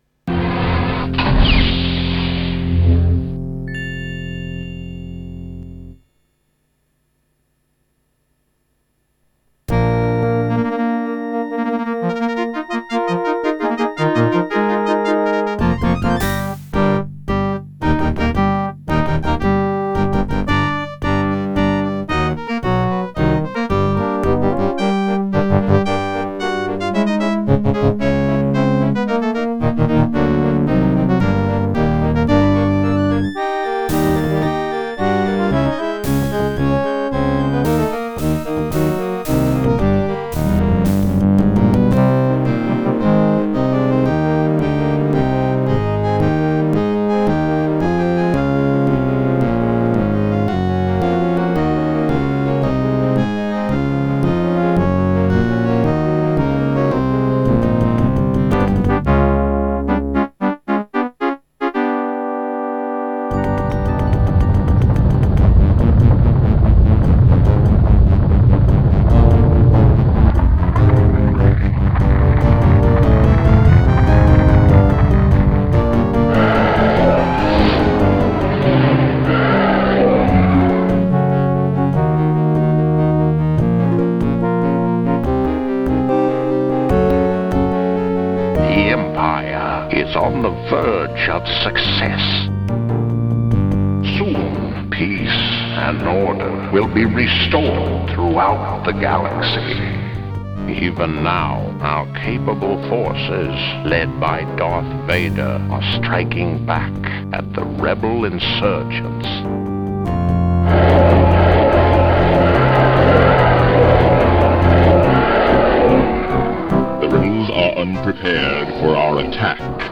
Sound Blaster 16 (CT2770)